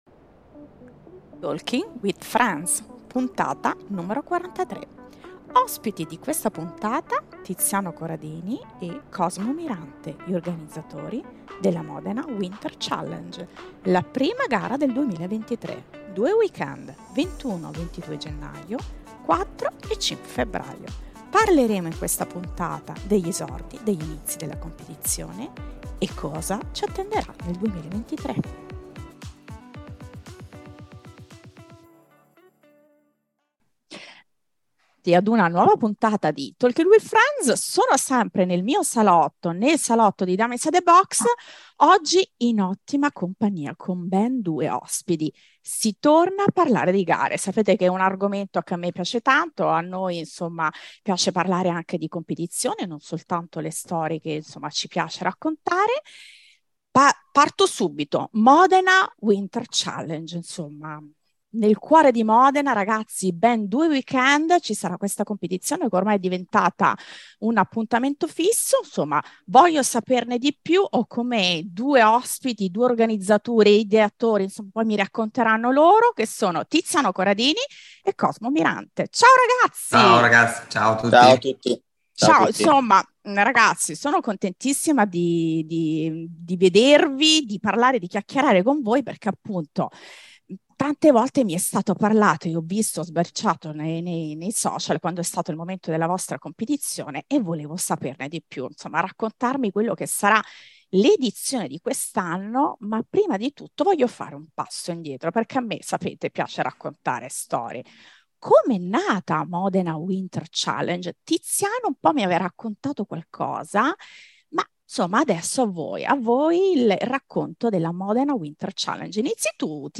Intervista agli organizzatori della competizione che raccontano la storia della competizione e alcune novità per edizione 2023.